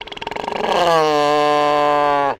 На этой странице собраны разнообразные звуки морского слона – от мощного рева самцов до нежных голосов детенышей.
Северный морской слон гигант океана